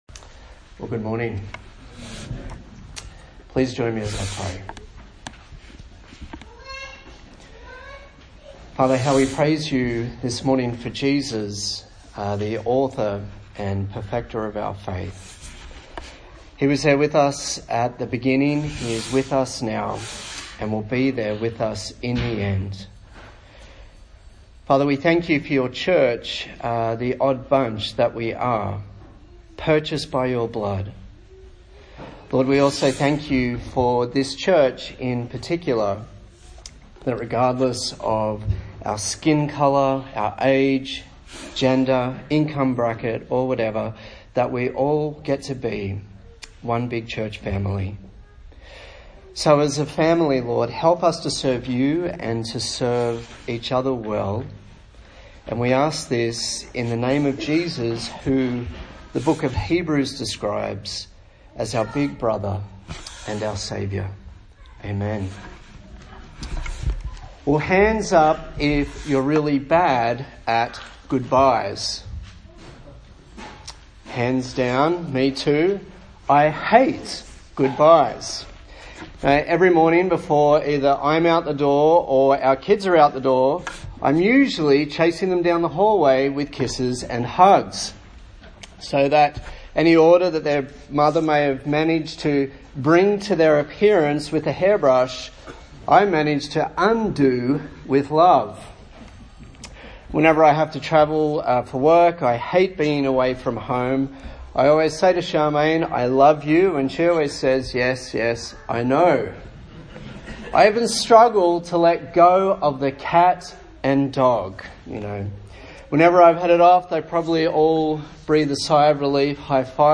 A sermon in the series on the book of 1 Corinthians
Service Type: Sunday Morning